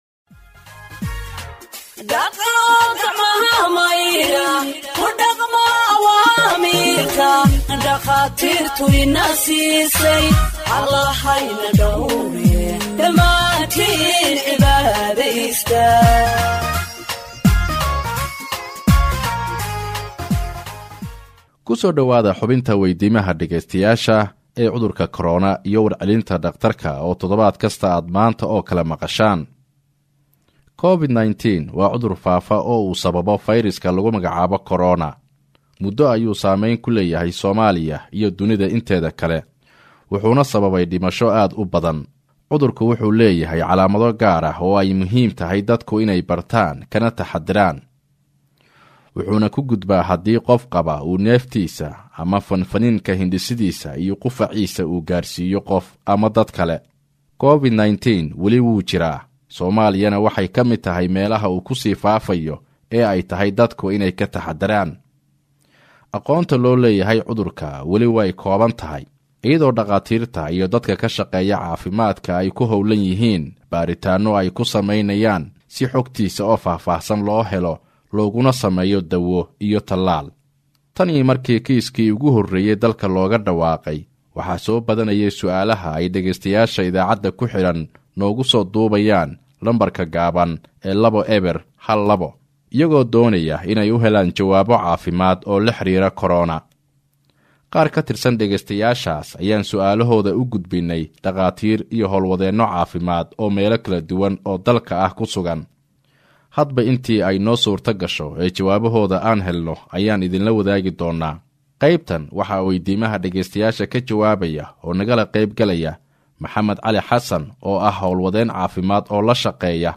Health expert answers listeners’ questions on COVID 19 (42)
HEALTH-EXPERT-ANSWERS-LISTENERS-QUESTIONS-ON-COVID-19-42.mp3